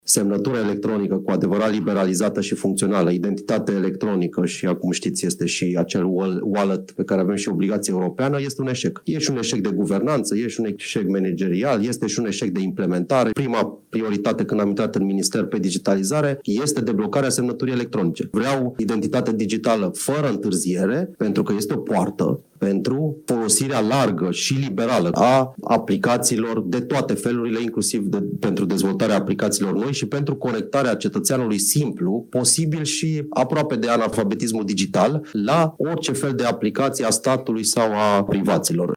Proaspătul ministru al Economiei susține că digitalizarea este prioritară pentru Guvern, pentru a-i ajuta pe oameni să se conecteze la aplicațiile statului, dar și pentru mediul de afaceri – pentru o interacțiune mai ușoară cu instituțiile publice. Irinu Darău, la Consiliul Național al IMM-urilor din România, unde a fost lansat Indexul Digitalizării României – un instrument de monitorizare a performanței digitale a IMM-urilor.